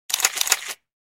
Photo-click-sound-effect.mp3